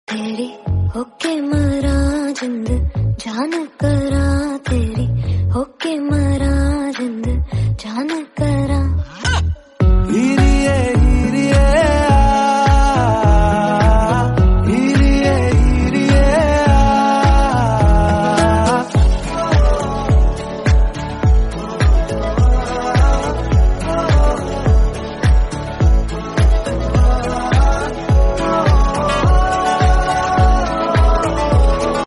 Ringtones Category: Top Ringtones